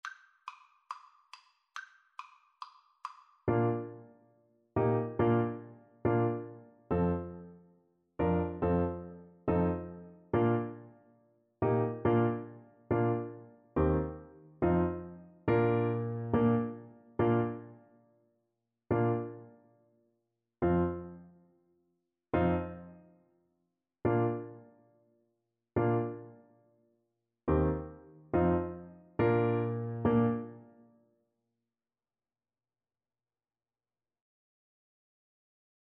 4/4 (View more 4/4 Music)
Fast = c. 140